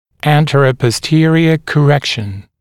[ˌæntərəpɔs’tɪərɪə kə’rekʃn][ˌэнтэрэпос’тиэриэ кэ’рэкшн]коррекция в сагиттальной плоскости